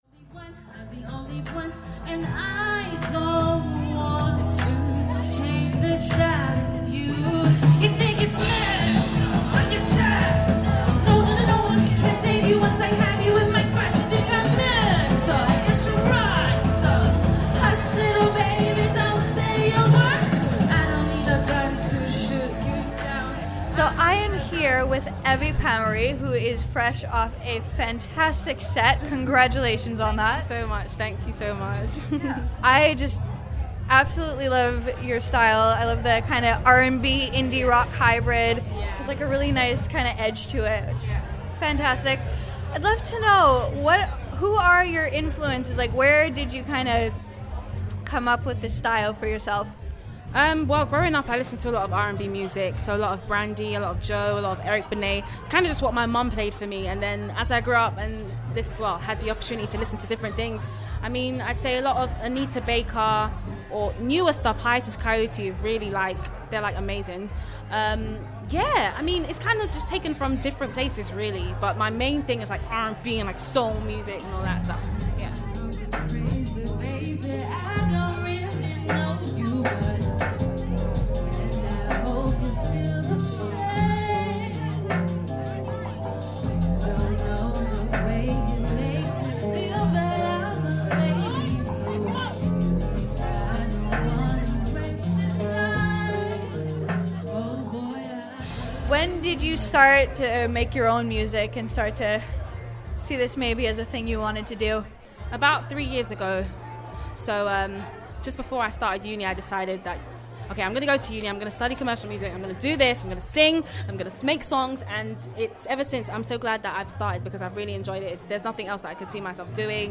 The Commercial Music Society's first gig took place on Monday March 29th. We went along to not only support them but grab a few interviews.